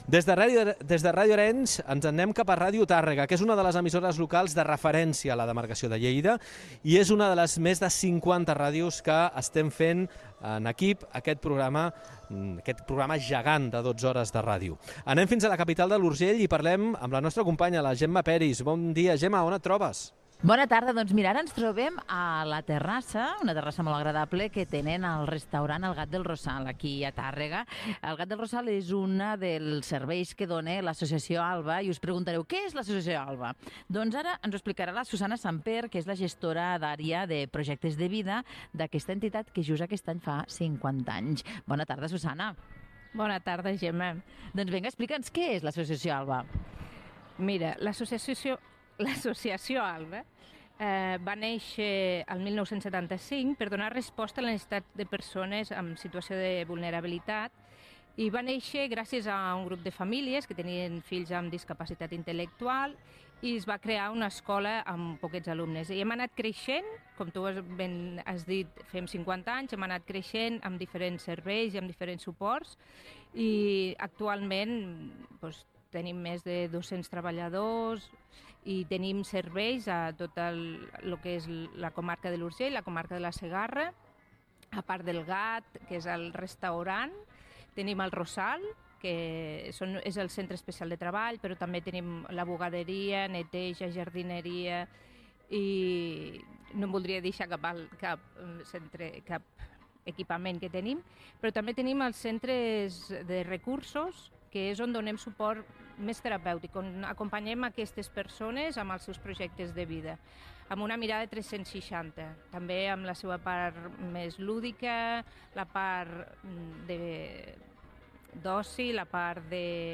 Gènere radiofònic
Entreteniment
FM